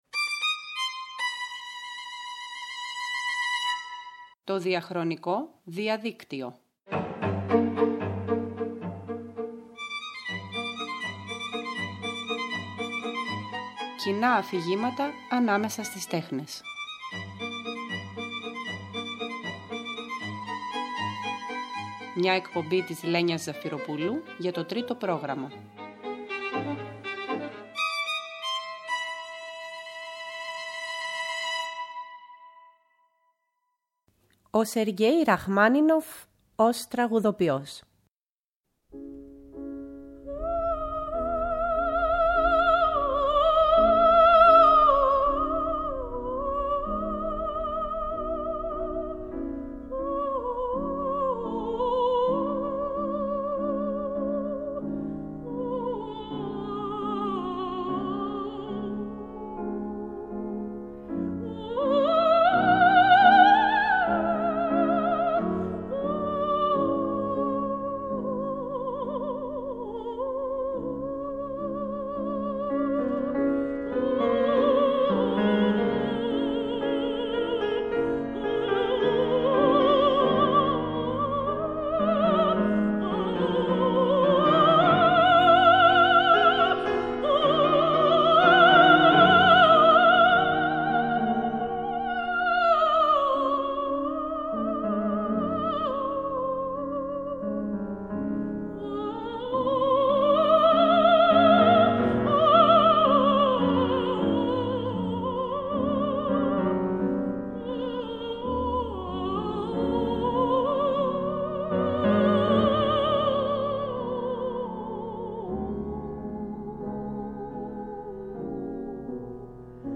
Ιριδίζοντα χρώματα ανάμεσα στον ρομαντισμό και τον ιμπρεσιονισμό, υψηλό αίσθημα, άφοβες, γενναίες χειρονομίες και πολυτελής δεξιοτεχνία: η πληθωρική προσωπικότητα του συνθέτη-πιανίστα που εμμένει δυναμικά και πεισματικά στην παρακαταθήκη του μακρού 19ου αιώνα, αναγνωρίζεται και στα τραγούδια και τις όπερές του όσο και στην πιανιστική του μουσική.